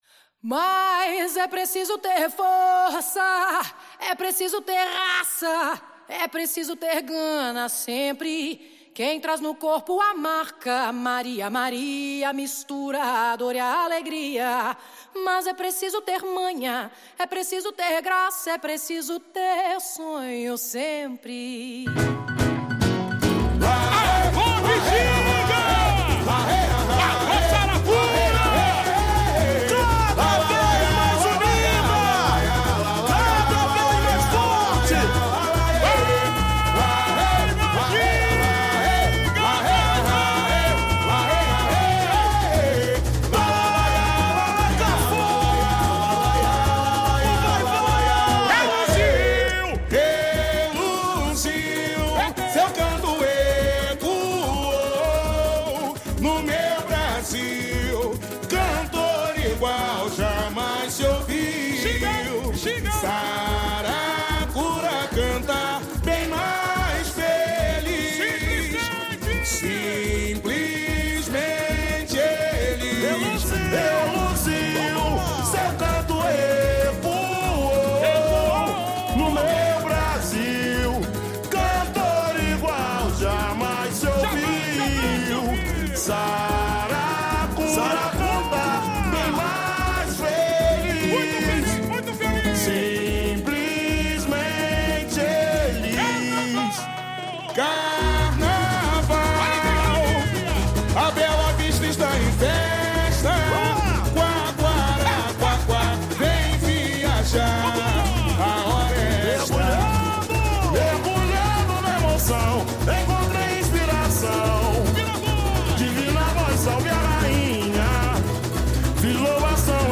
Intérpretes: